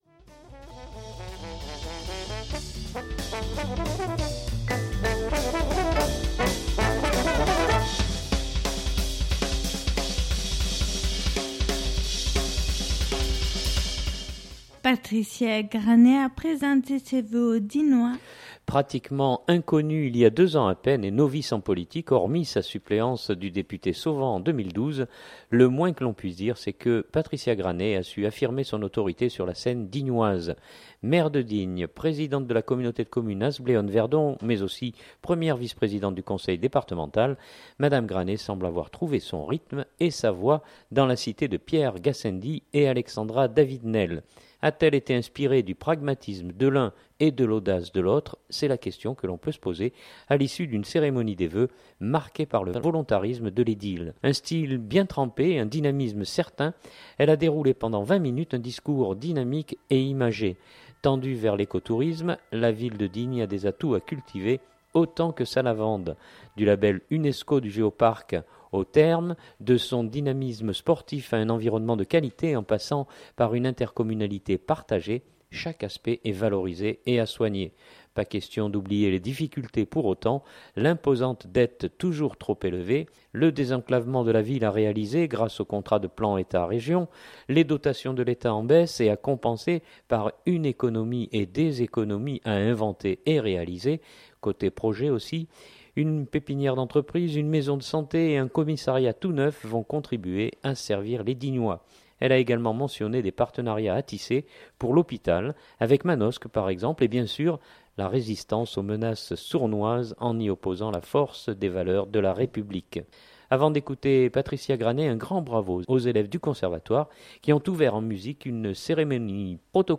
Je vous propose d’écouter Patricia Granet interviewée à l’issue de son intervention.